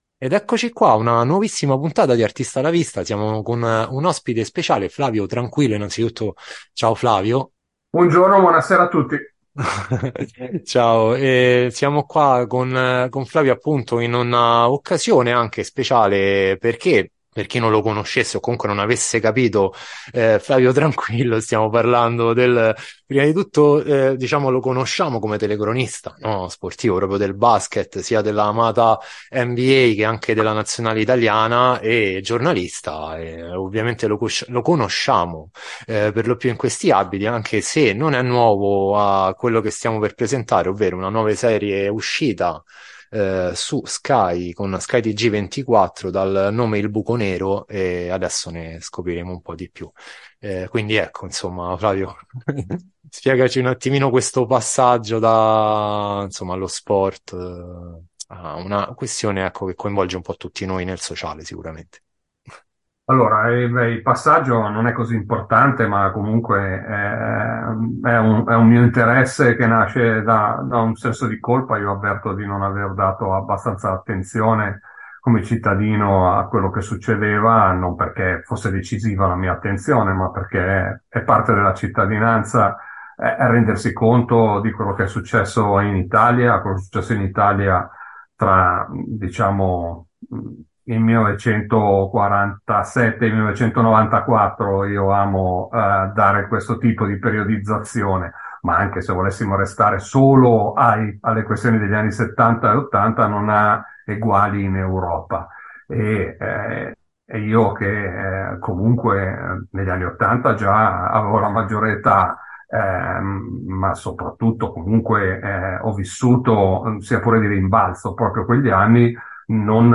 Artista-a-la-Vista-Intervista-Flavio-Tranquillo.mp3